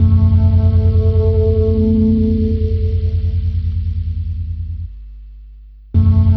9 Harsh Realm Bass Fadeout.wav